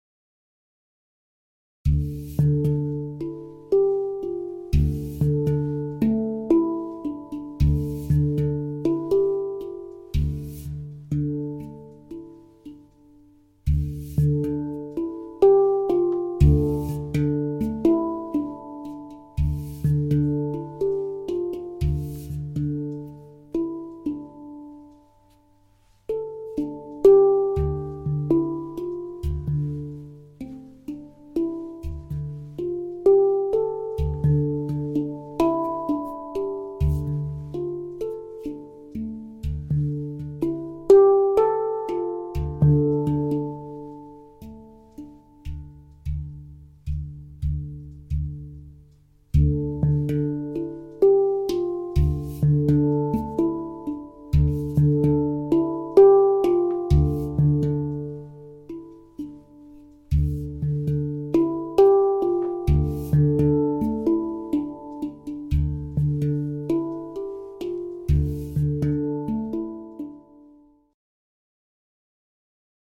2e generatie hang (2007) verticaal gespeeld op schoot
Hang_2007_vertical.mp3